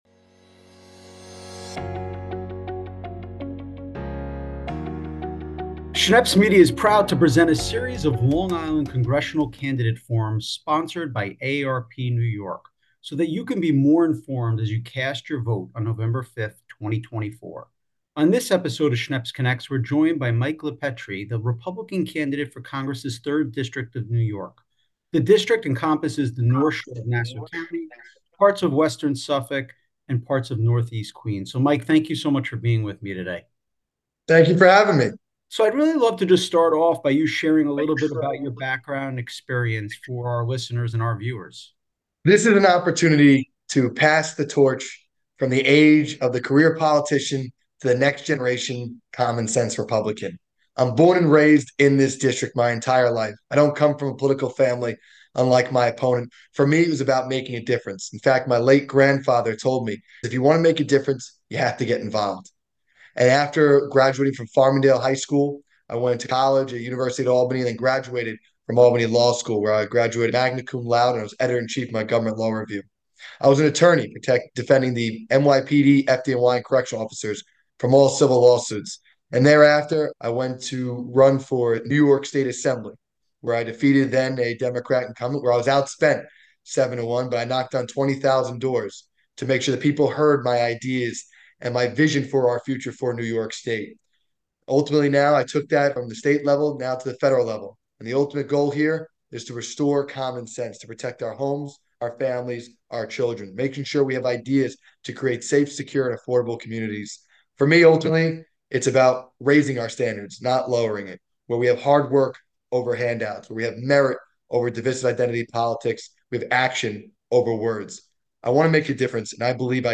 Schneps Connects a conversation with NY-3 Congressional Candidate Mike LiPetri